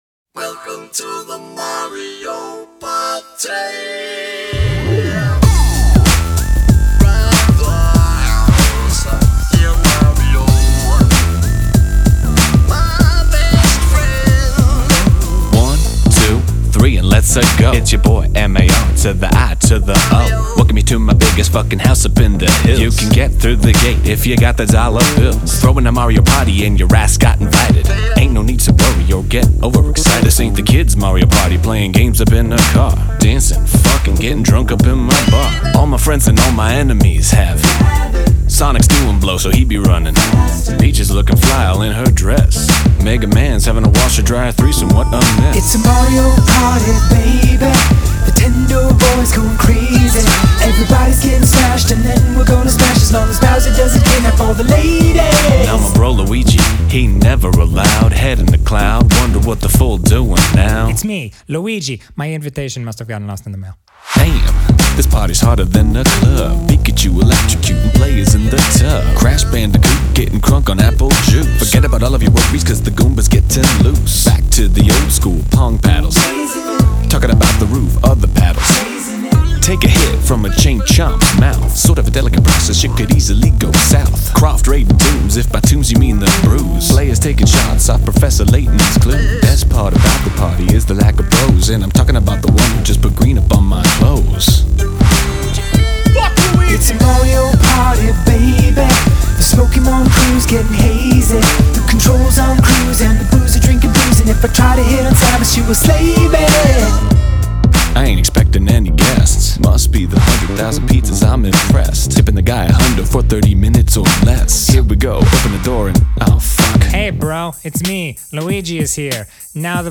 BPM95-95
Audio QualityPerfect (High Quality)
Full Length Song (not arcade length cut)